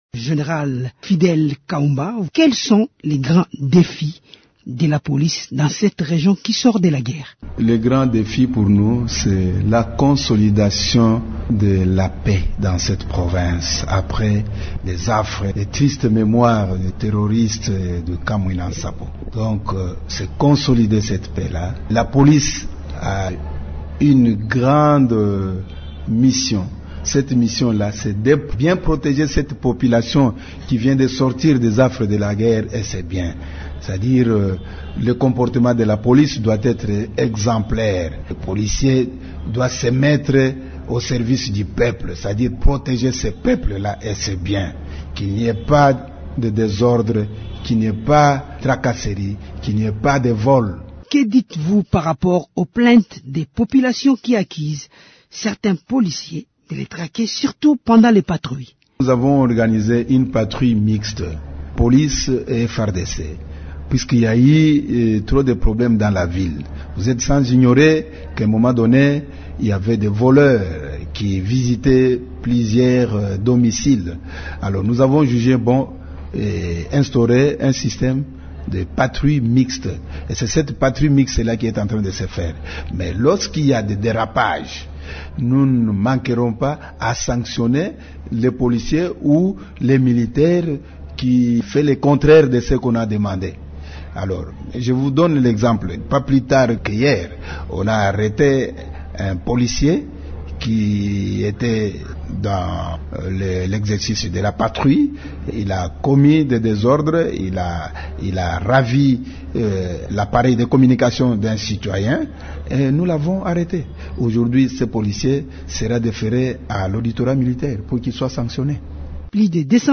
Le général Kaumba est l’invité de Radio Okapi.